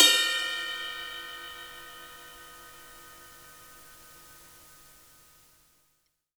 RIDE_hard_01.WAV